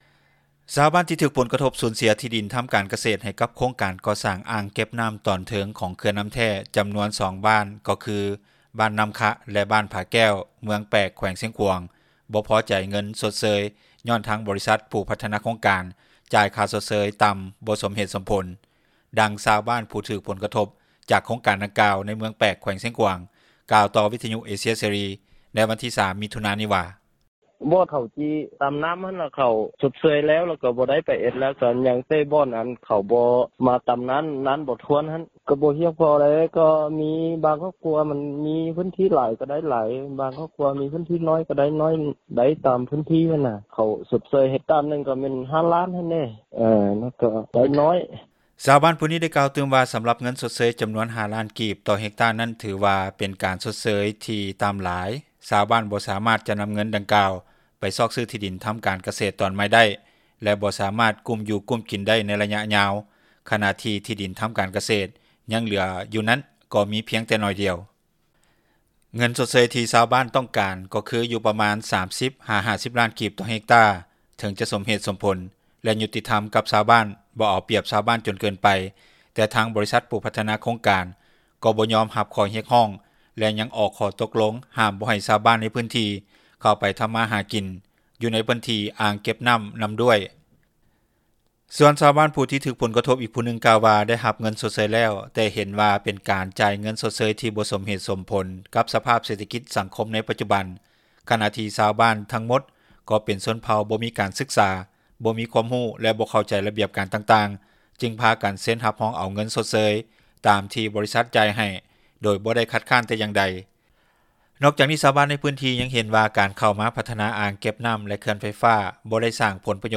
ດັ່ງຊາວບ້ານຜູ້ນຶ່ງທີ່ຖືກຜົລກະທົບ ຈາກໂຄງການດັ່ງກ່າວໃນເມືອງແປກ ແຂວງຊຽງຂວາງ ກ່າວຕໍ່ວິທຍຸເອເຊັຽເສຣີໃນມື້ວັນທີ 3 ມິຖຸນາ ນີ້ວ່າ:
ດັ່ງເຈົ້າໜ້າທີ່ ຜແນກຊັພຍາກອນທັມຊາດ ແລະສິ່ງແວດລ້ອມ ແຂວງຊຽງຂວາງທ່ານນຶ່ງ ກ່າວຕໍ່ວິທຍຸເອເຊັຽເສຣີໃນວັນທີ 03 ມິຖຸນານີ້ວ່າ: